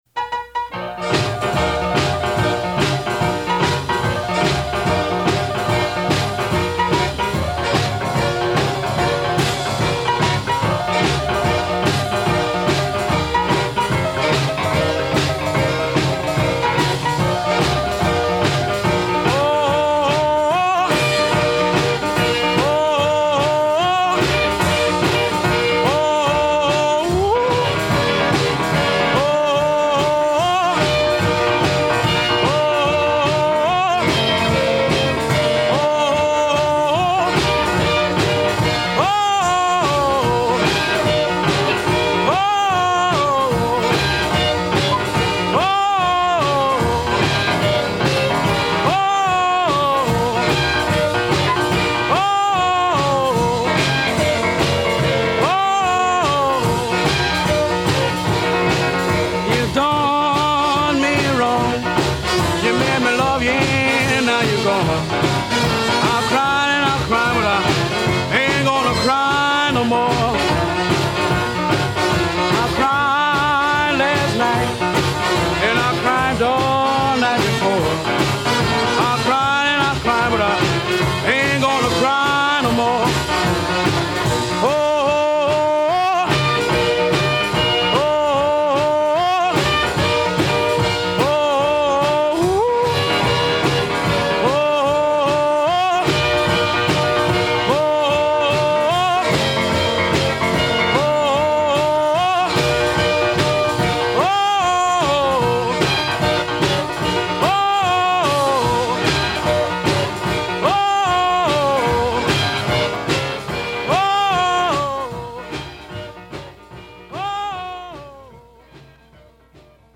Американский пианист